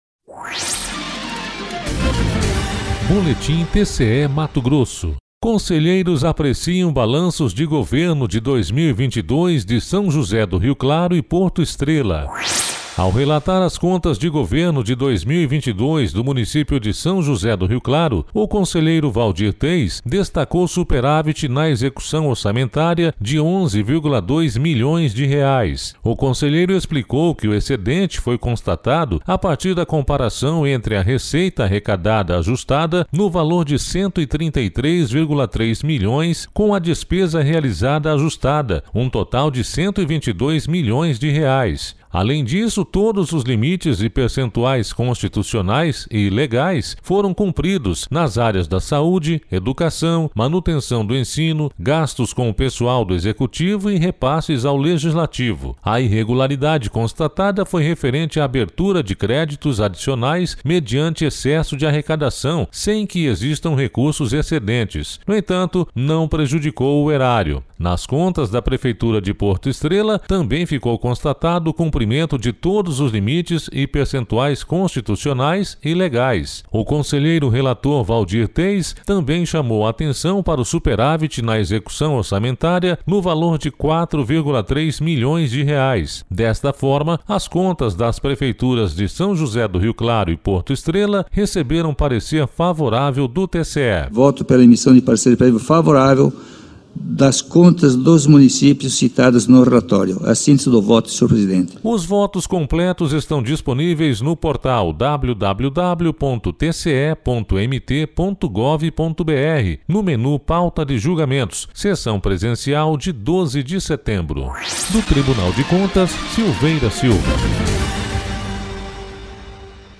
Sonora: Waldir Júlio Teis – conselheiro do TCE-MT